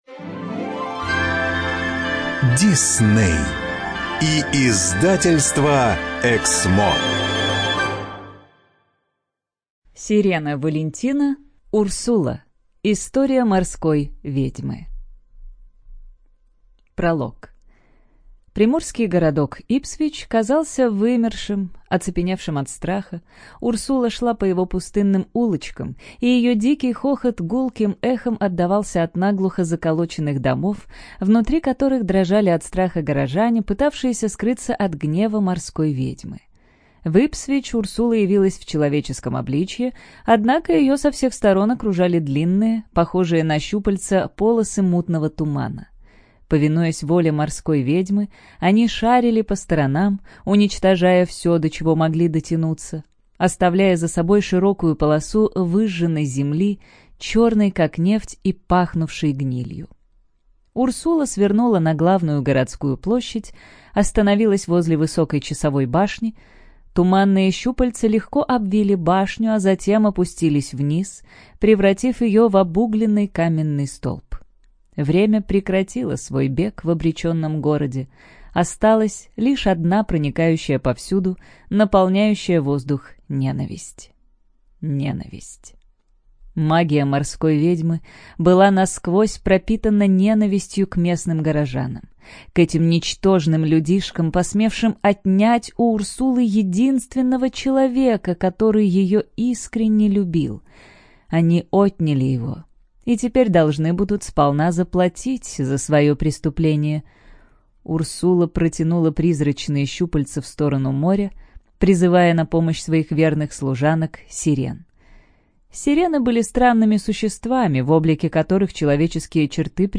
ЖанрСказки
Студия звукозаписиЭКСМО